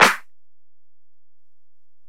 Snare (58).wav